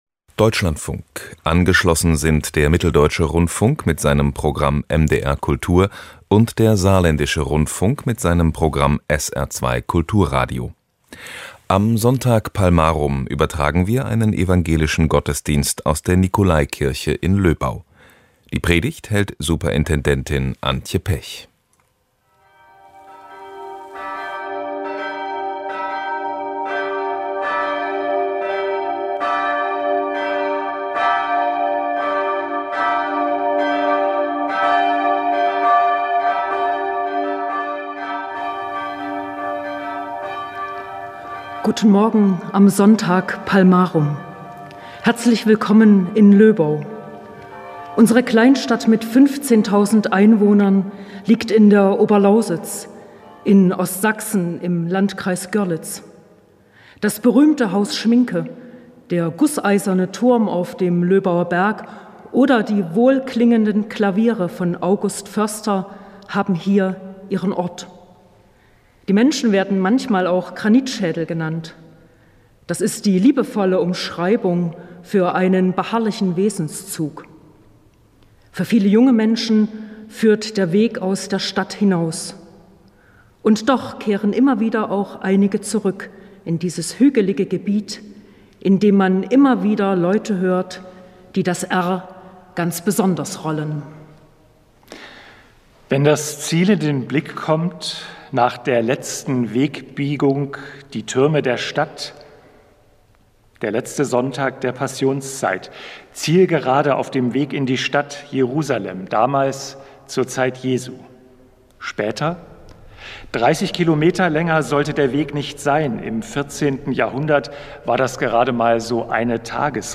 Evangelischer Gottesdienst